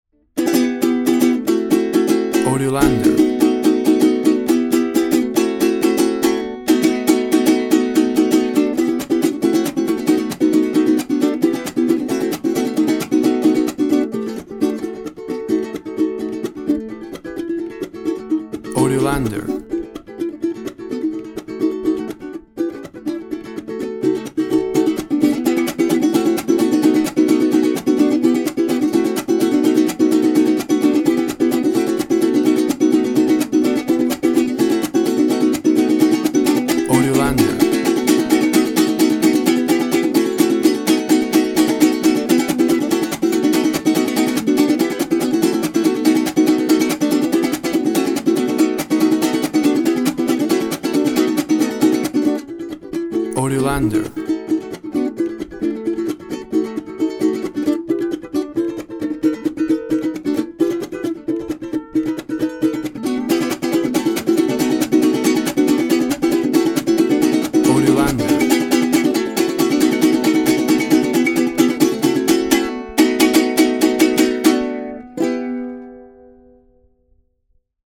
“Joropo” Rhythm.
WAV Sample Rate 24-Bit Stereo, 44.1 kHz
Tempo (BPM) 180/190